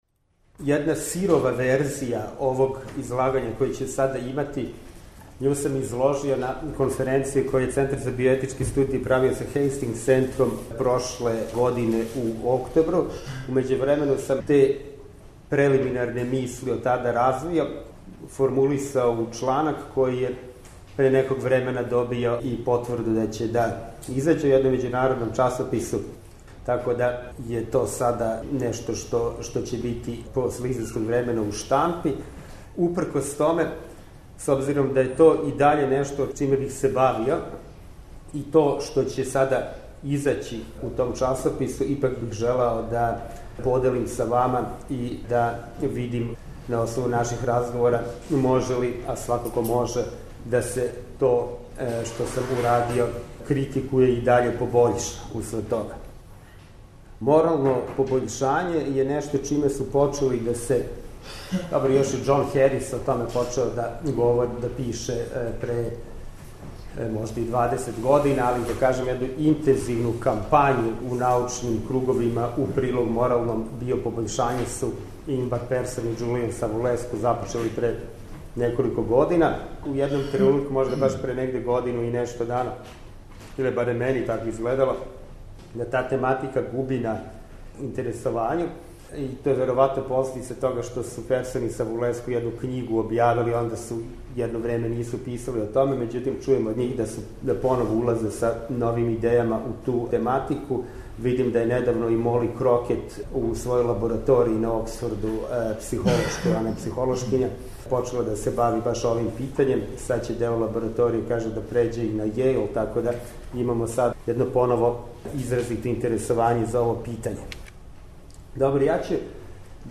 преузми : 11.36 MB Трибине и Научни скупови Autor: Редакција Преносимо излагања са научних конференција и трибина.